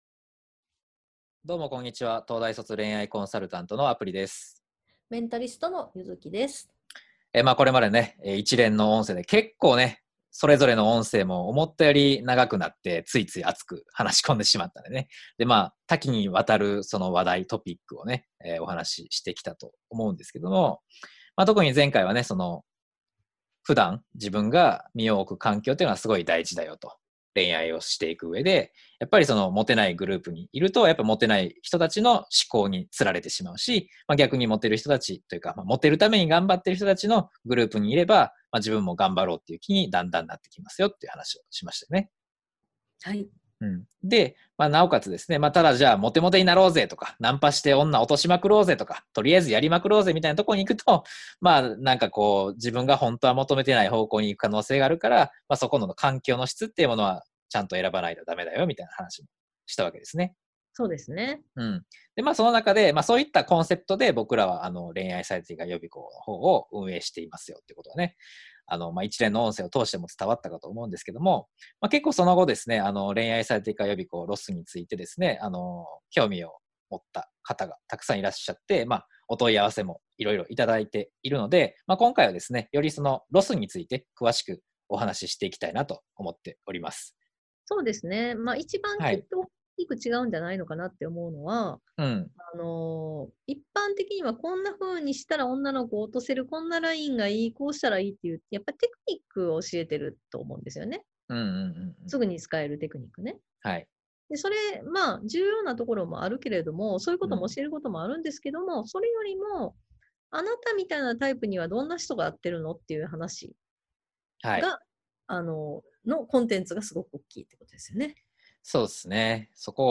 まずはこちらの対談音声をお聞きください。